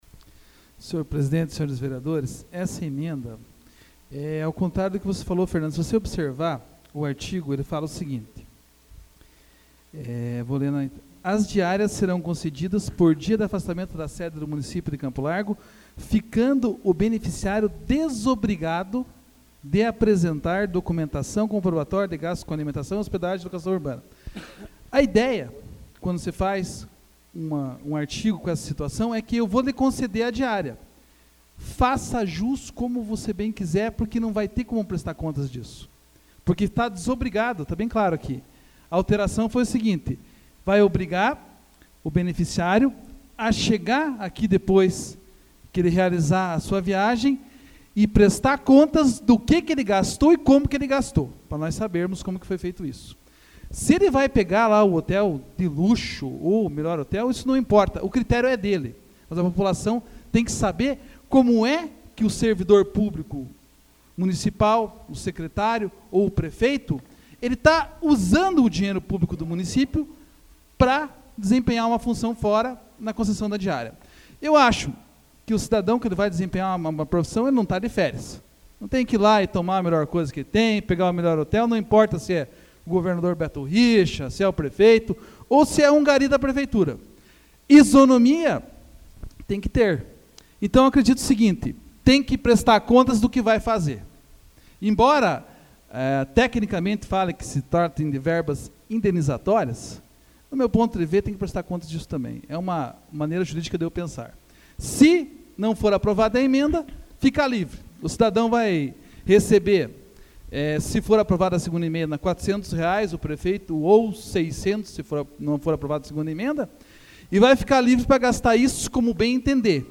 Discussão emenda 01 AVULSO 03/06/2014 João Marcos Cuba